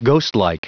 Prononciation du mot ghostlike en anglais (fichier audio)
ghostlike.wav